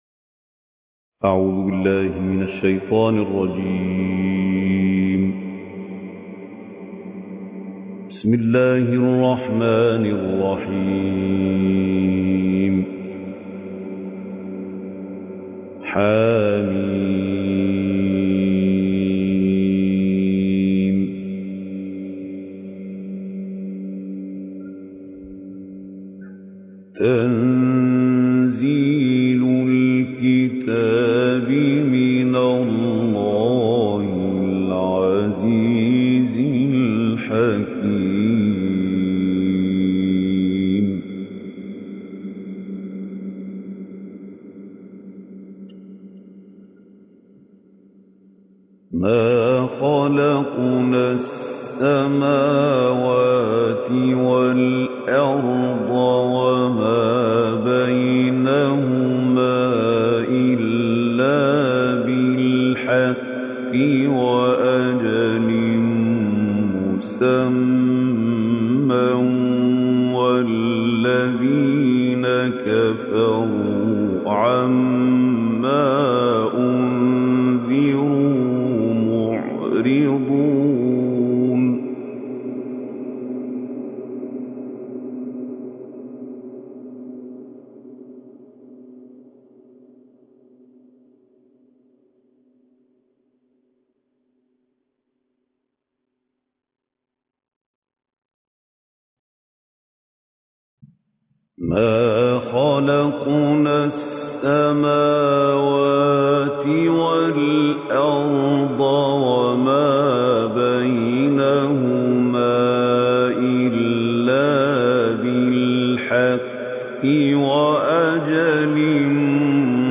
Reciter Mahmoud Khaleel El Hussary